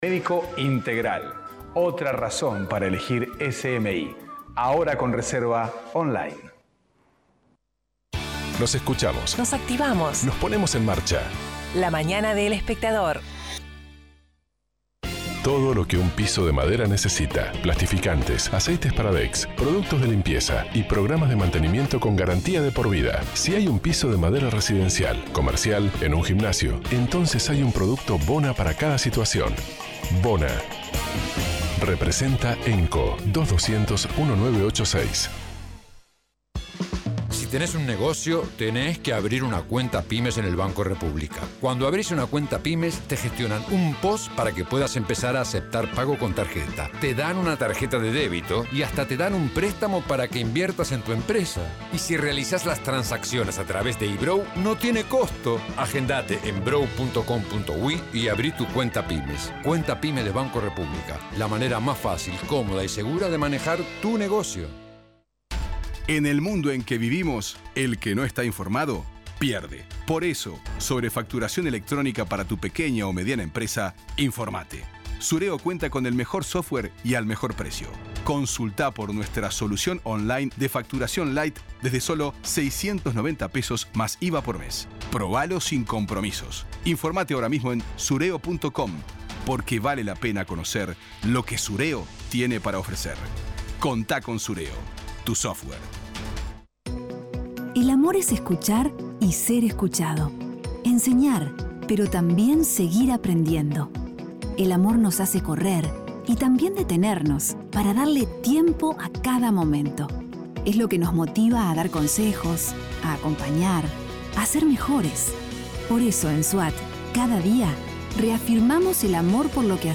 El embajador de México en Uruguay, Francisco Arroyo Vieyra, habló en la Mañana de El Espectador sobre la realidad de su país y el papel que juega el Tratado de Libre Comercio (TLC) con Uruguay.
Escuche la entrevista de La Mañana: